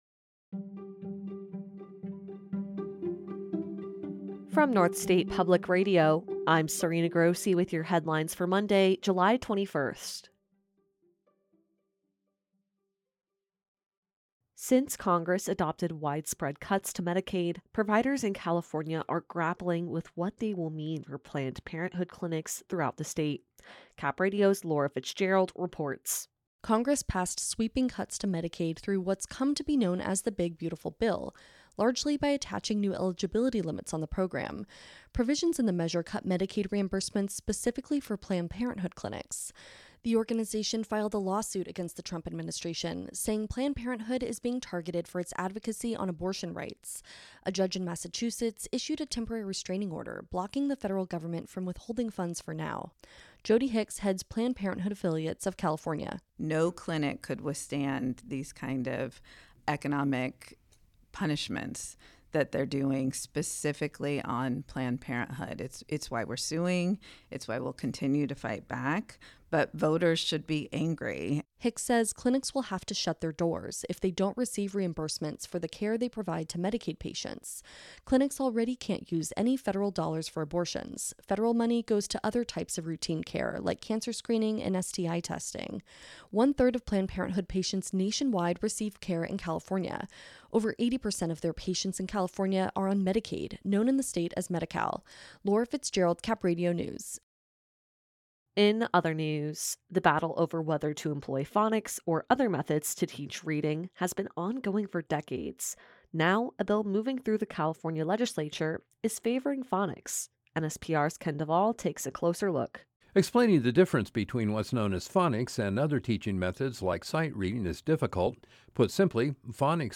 A daily podcast from NSPR featuring the news of the day from the North State and California in less than 10 minutes.